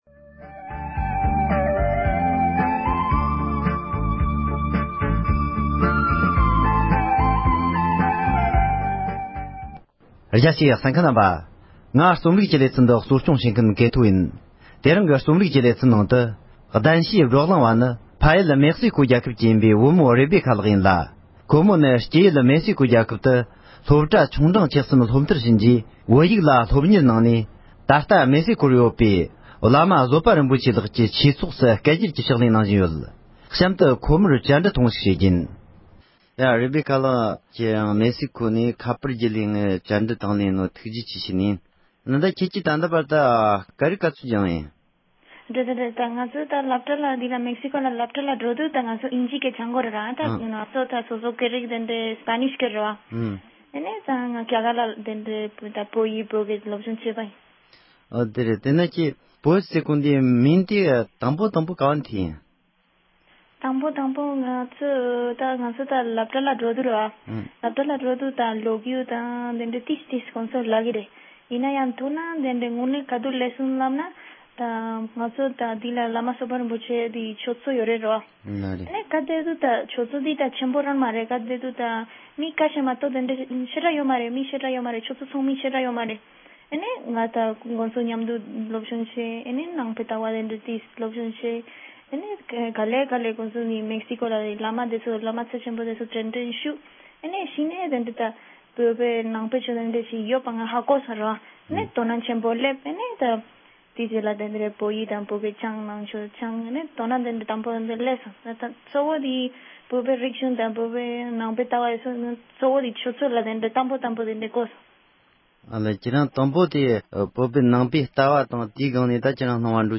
བཅར་འདྲི་ཞུས་པ།